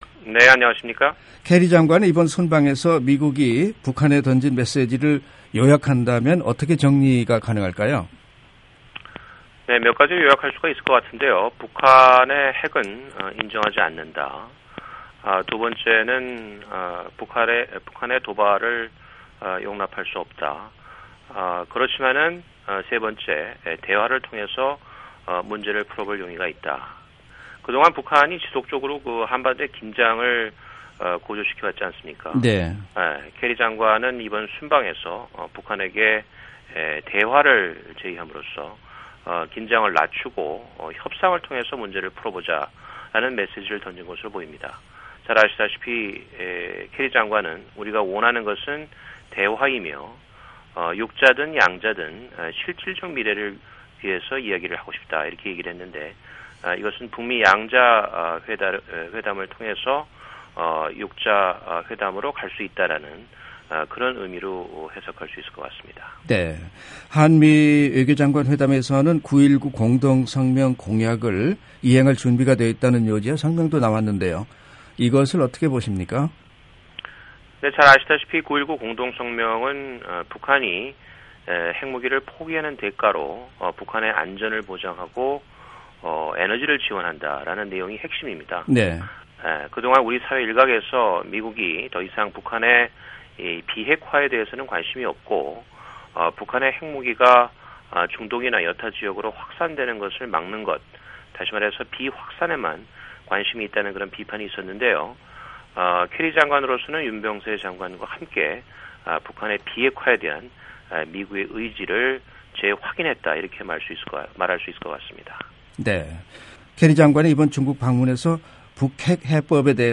[인터뷰] 김성한 전 외교부 차관